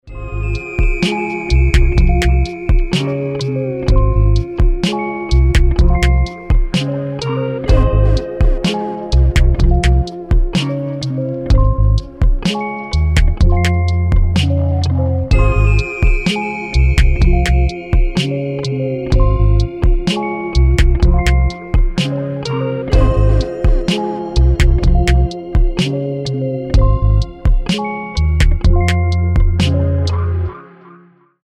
Рингтоны Без Слов
Рэп Хип-Хоп Рингтоны